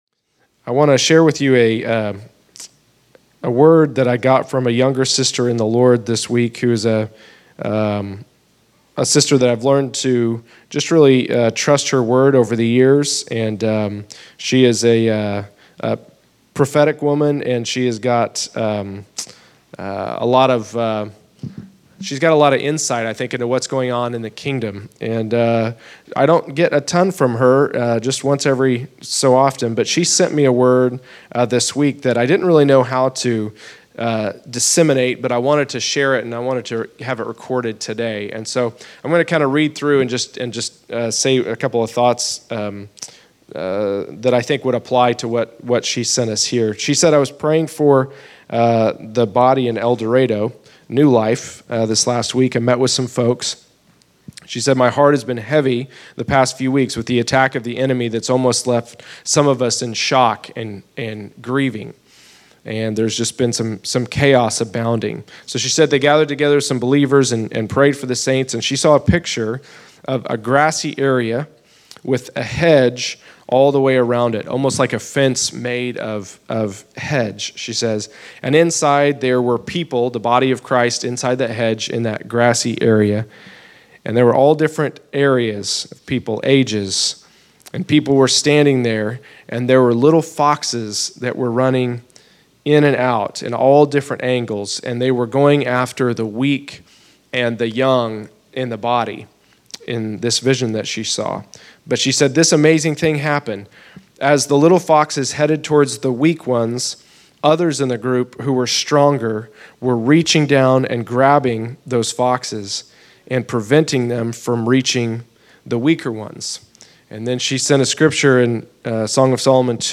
Category: Exhortation